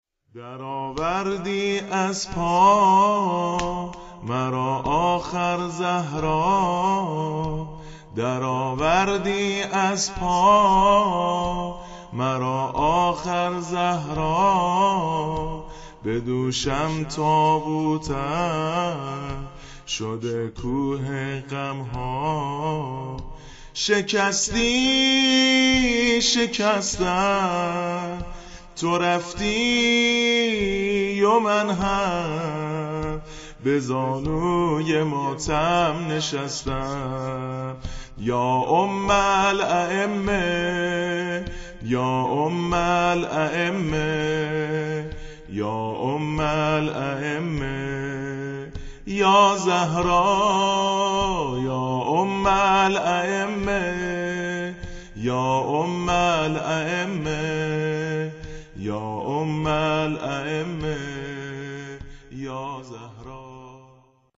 فاطمه الزهرا شام غریبان نوحه